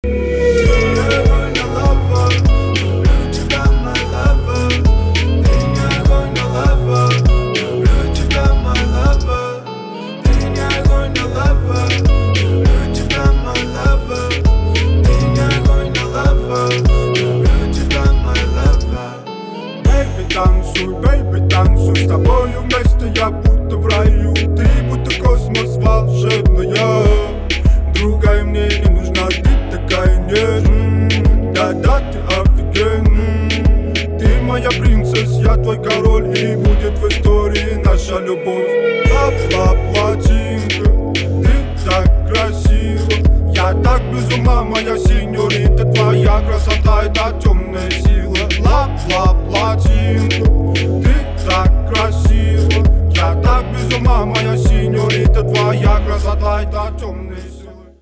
• Качество: 320, Stereo
поп
лирика
dance